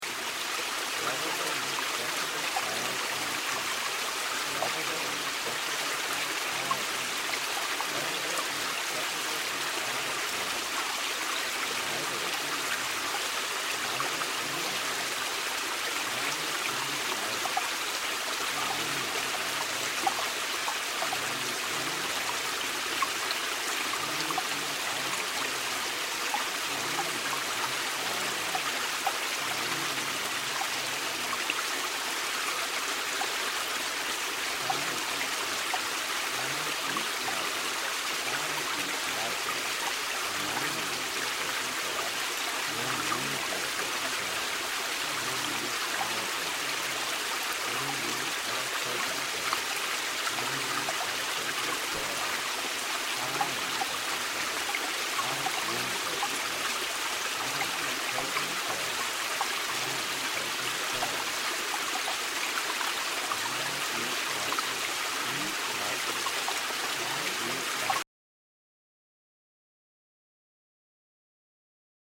Weight Control Subliminals contains healthy affirmations about better eating habits embedded subliminally.